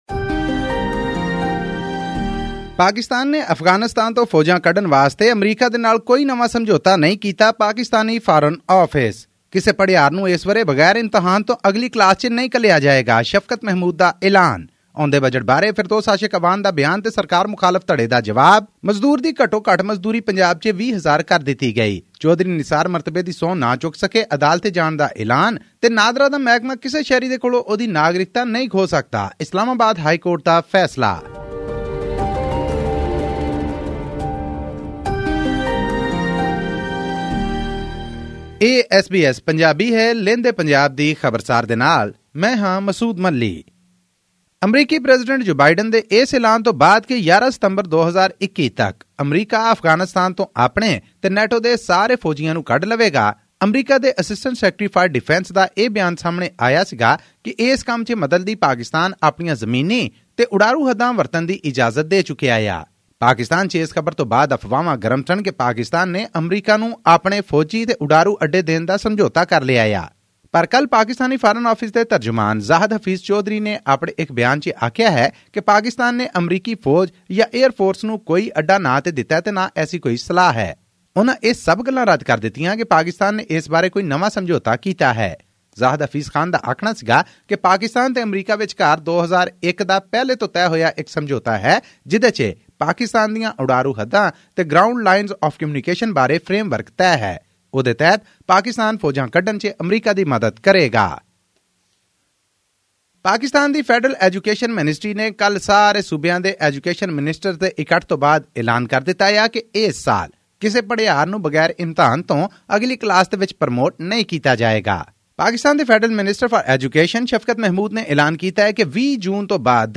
Pakistan government has dismissed the possibility of any US military or airbase construction on its territory ahead of international military pullout from Afghanistan. Tune into this podcast for a weekly news update from Pakistan.